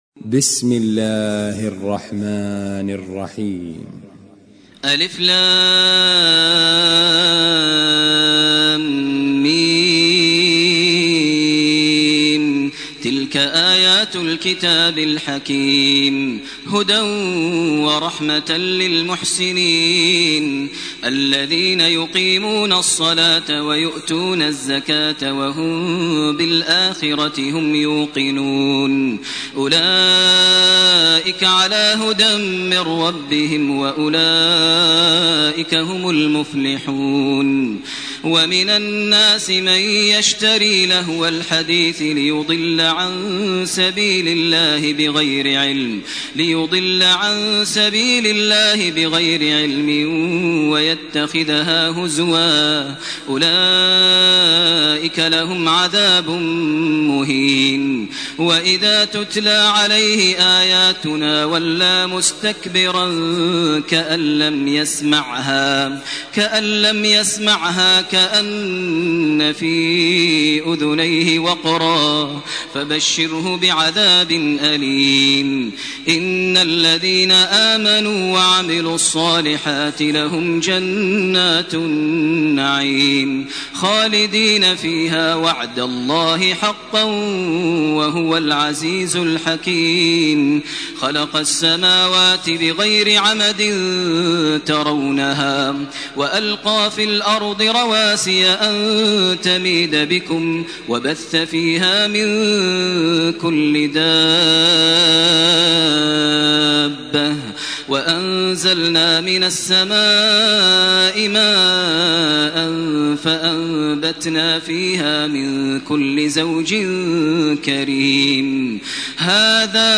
سورة لقمان و سورة السجدة > تراويح ١٤٢٨ > التراويح - تلاوات ماهر المعيقلي